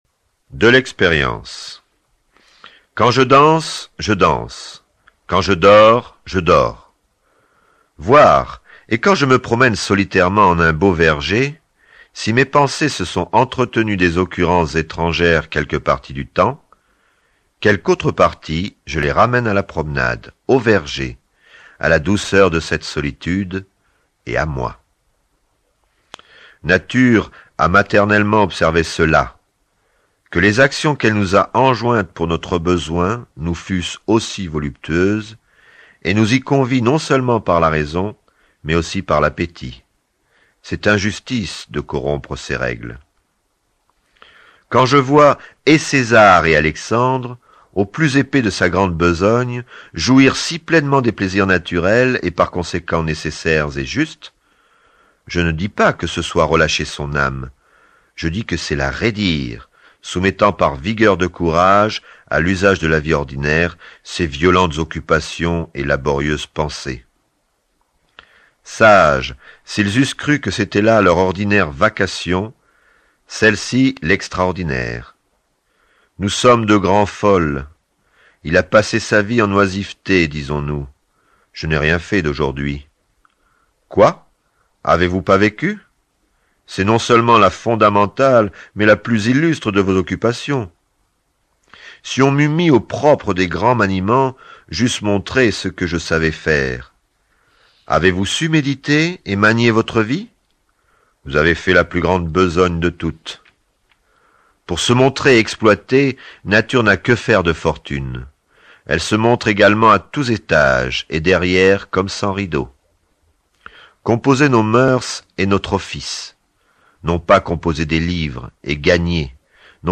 Diffusion distribution ebook et livre audio - Catalogue livres numériques